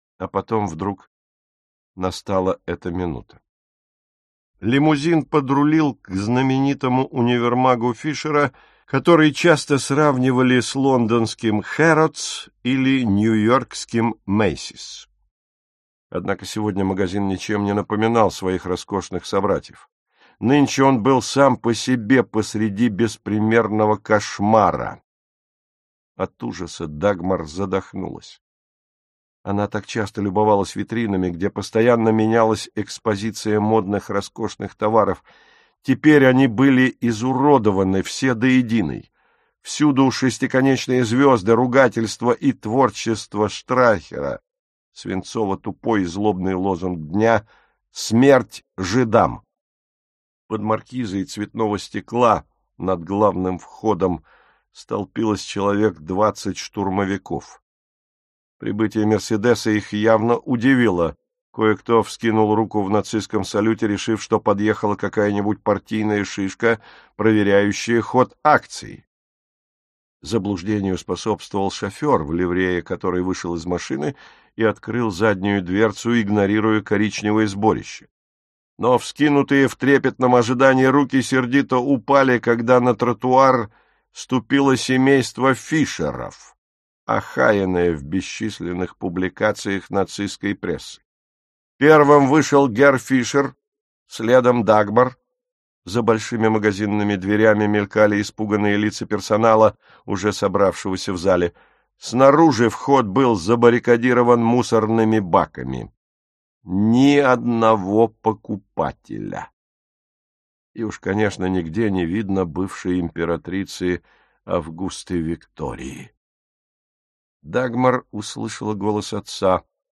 Аудиокнига Два брата - купить, скачать и слушать онлайн | КнигоПоиск